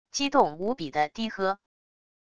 激动无比地低喝wav音频